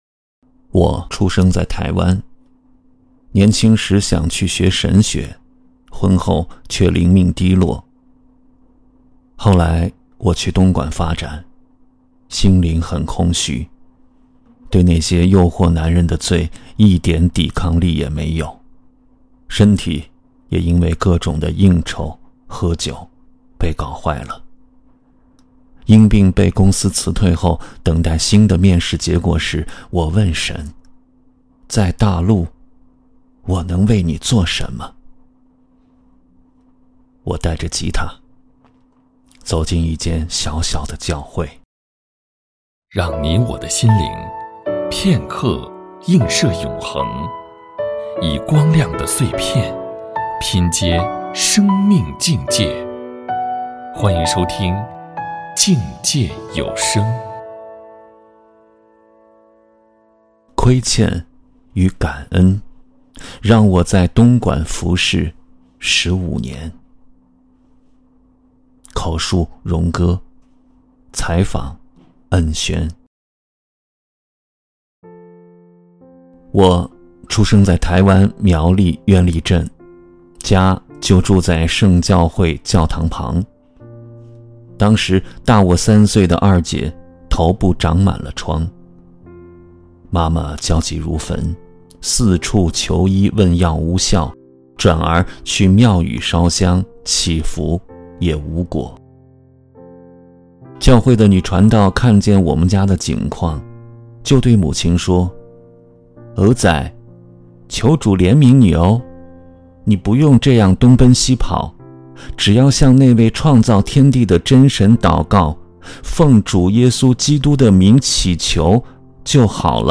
口述实录